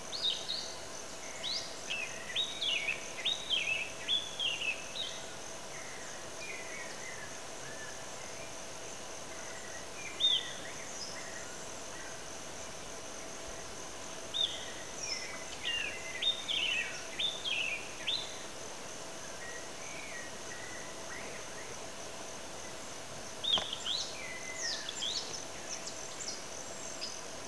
SABIÁ-PRETO
Turdus flavipes (Vieillot, 1818)